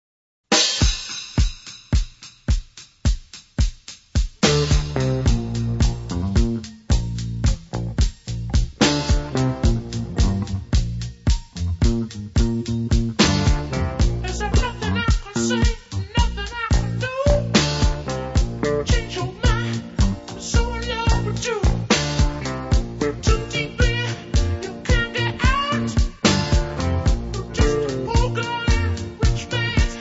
• rock